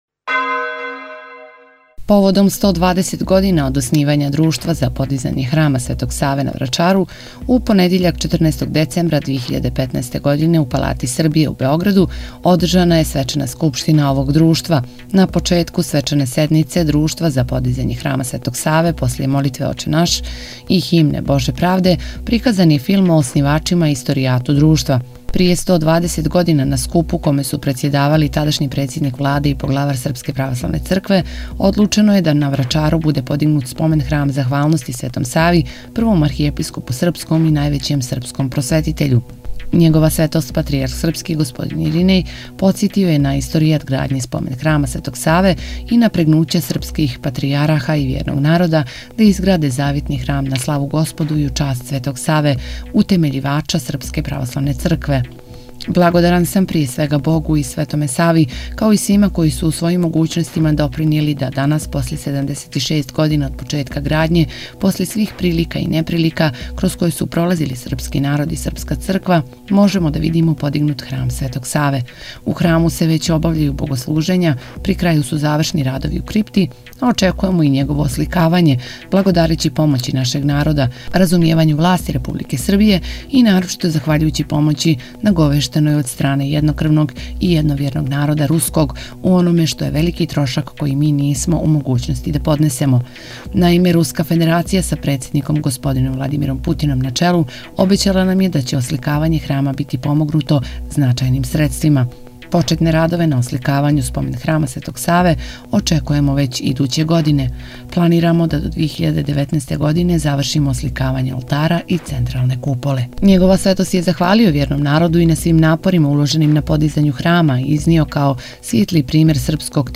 Свечано обиљежено 120 година Друштва за подизање храма Светог Саве на Врачару Tagged: Из наше Цркве 14:05 минута (12.89 МБ) У понедјељак 14. децембра 2015. године у Палати Србија у Београду одржана је свечана Скупштина Друштва за подизање храма Светог Саве на Врачару којом је обиљежено 120 година рада. Свечана сједница је отворена молитвом Оче наш и химном Боже правде.